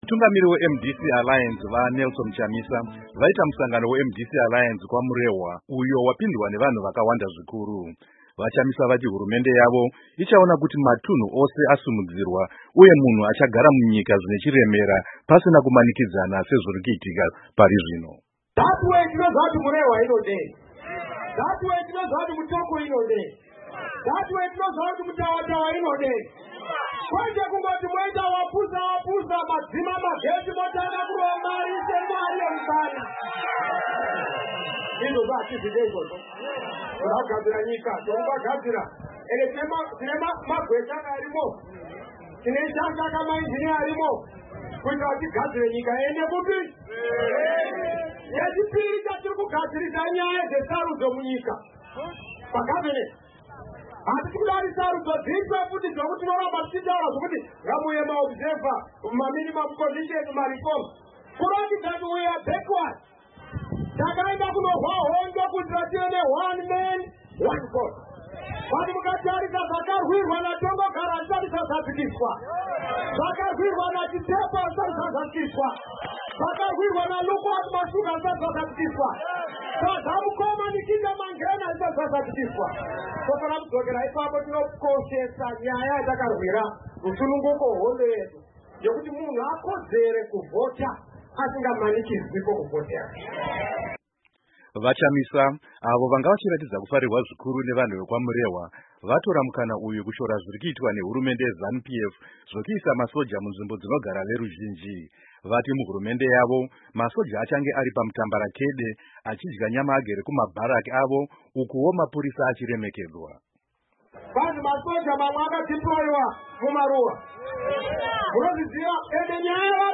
Mutungamiri we MDC Alliance, VaNelson Chamisa, vaita musangano weMDC Alliance kwaMurehwa mudunhu reMashonaland East.
Musangano uyu wapindwa nevanhu vakawanda pakatariswa kuti Murehwa yagara iri nzvimbo yeZanu PF.
VaChamisa Vachitaura kwaMurehwa kuMash East